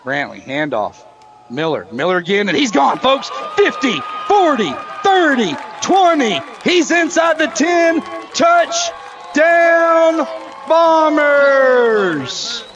Here’s how it sounded on Classic Hits 101.7.